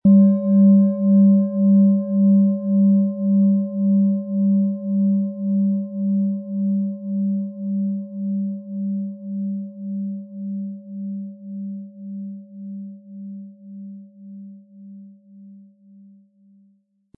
Tibetische Universal-Klangschale, Ø 15,7 cm, 400-500 Gramm, mit Klöppel
Wir haben diese Schale beim Aufnehmen angespielt und den subjektiven Eindruck, dass sie alle Körperregionen gleich stark anspricht.
Um den Original-Klang genau dieser Schale zu hören, lassen Sie bitte den hinterlegten Sound abspielen.
SchalenformBihar
HerstellungIn Handarbeit getrieben
MaterialBronze